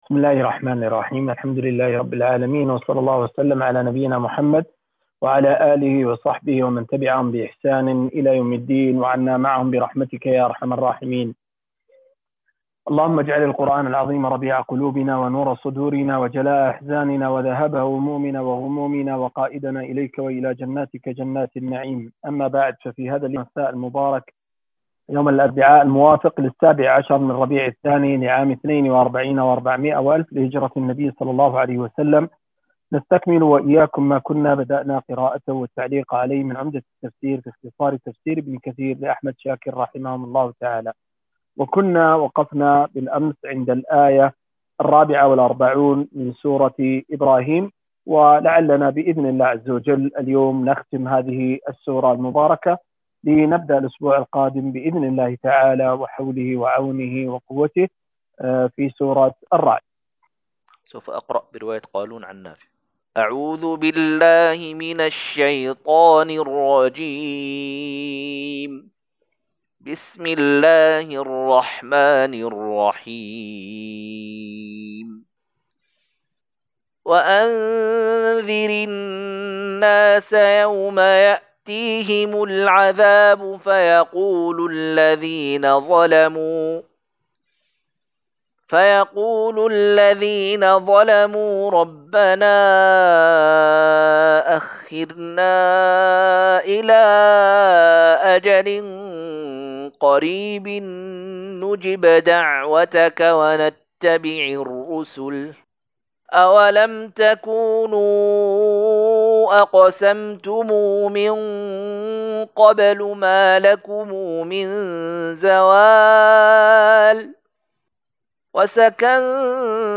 245- عمدة التفسير عن الحافظ ابن كثير رحمه الله للعلامة أحمد شاكر رحمه الله – قراءة وتعليق –